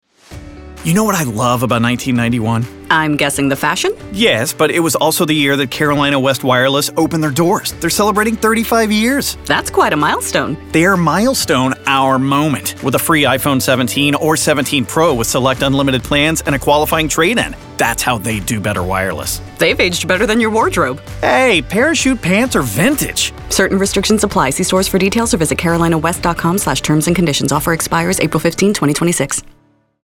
• Commercial VO